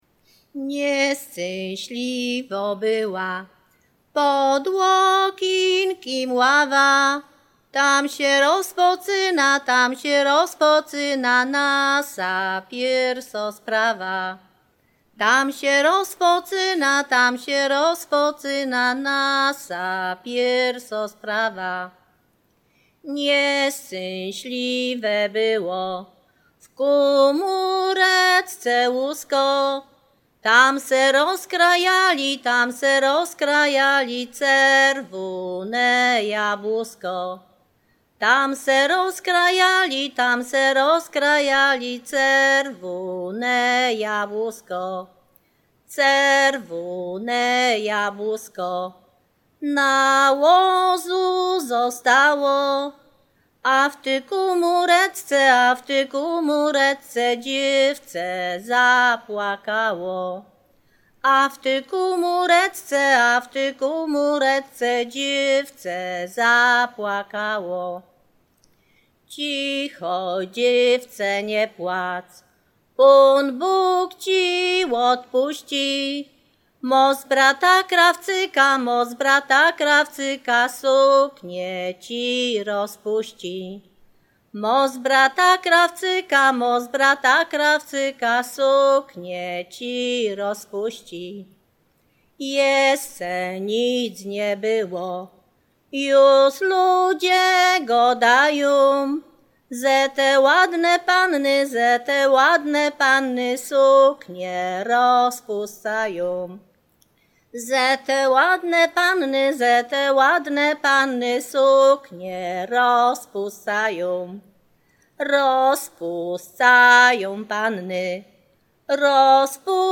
województwo łódzkie, powiat sieradzki, gmina Sieradz, wieś Chojne
Array liryczne miłosne